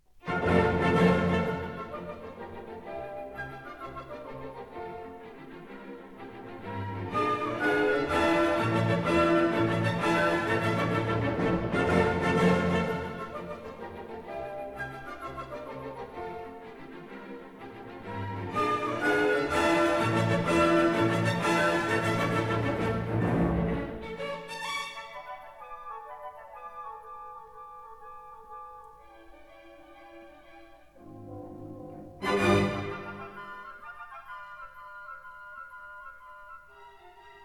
Stereo recording made in April 1961 at
Studio No.1, Abbey Road, London